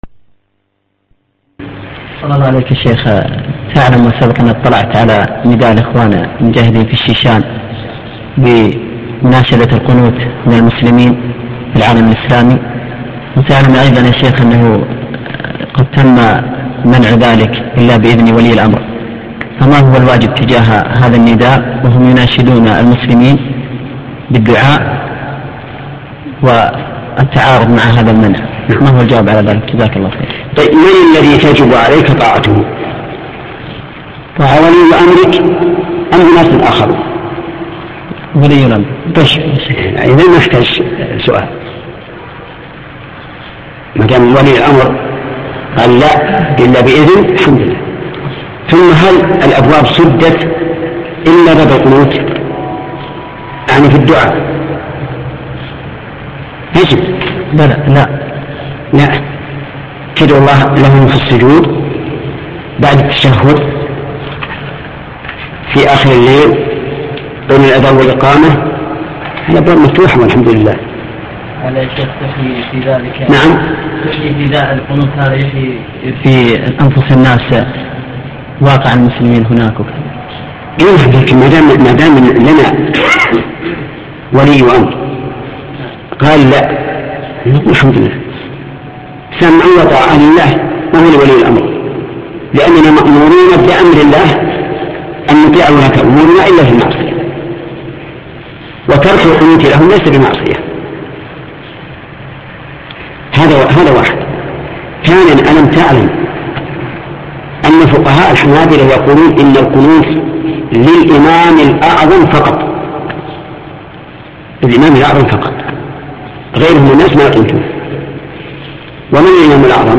حوار حول دعاء القنوت و أنه لا يجوز إلا بإذن ولي الأمر - الشيخ بن عثيمين : إمامنا هو فهد بن عبد العزيز